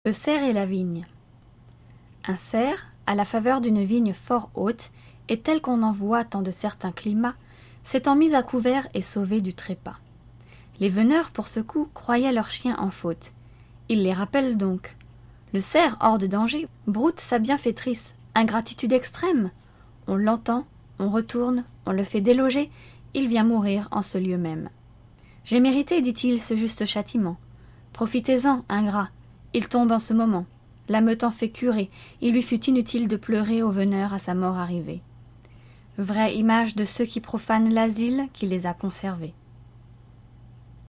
フランス人による朗読音声